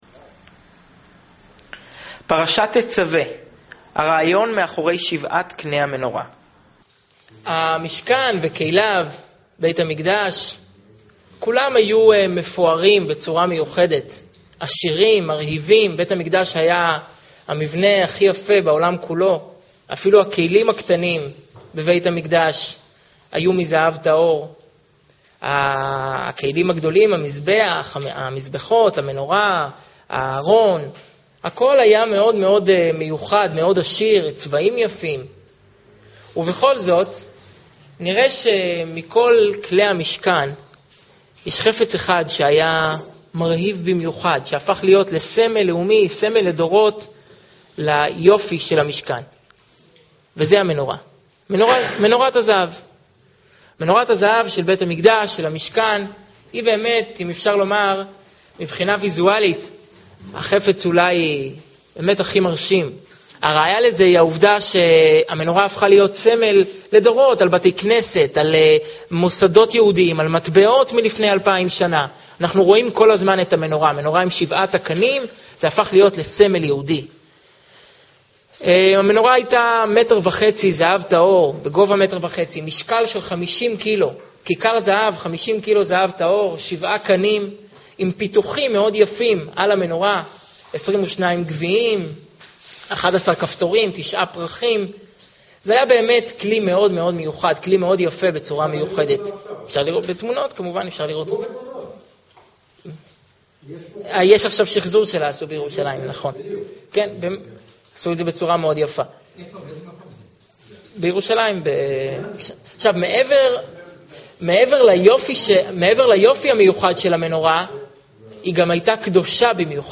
שיעור מרתק לפרשת תצווה